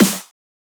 Snare 10 (First Of The Year).wav